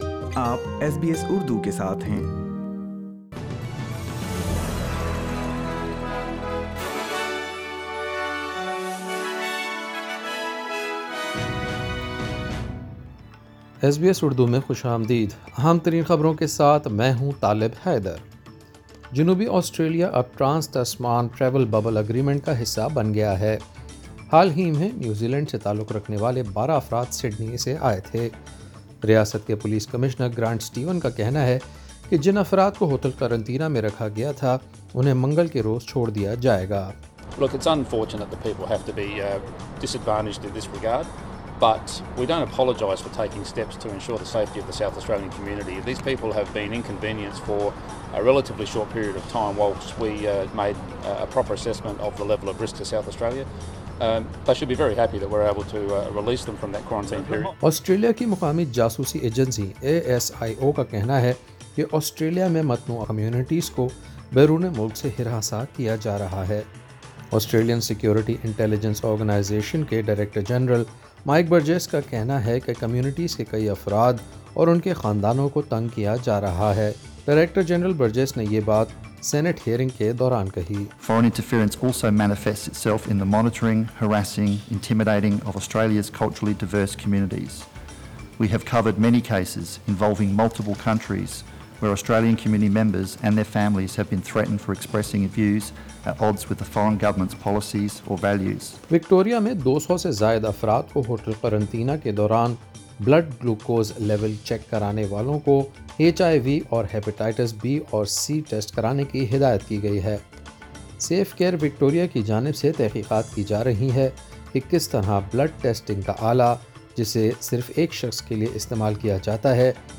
ایس بی ایس اردو خبریں 10 اکتوبر 2020